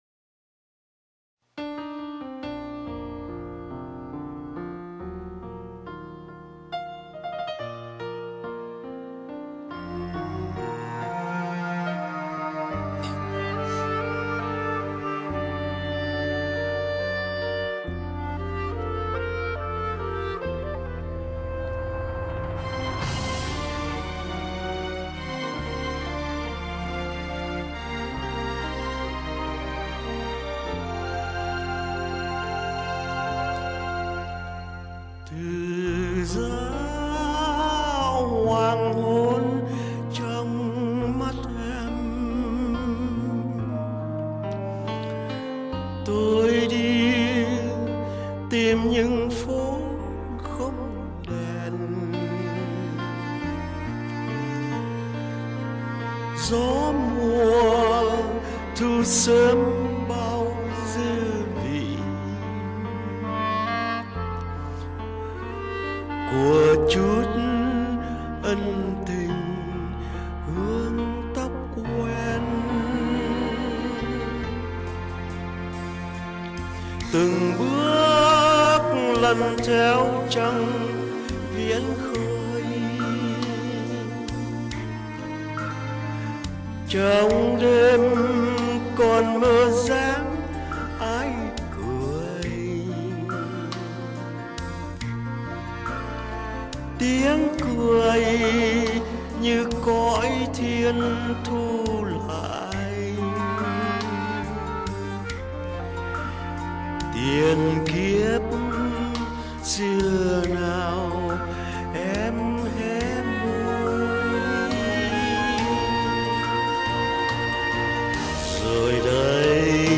TIẾNG HÁT ẤM, NGỌT NGÀO HAY TUYỆT.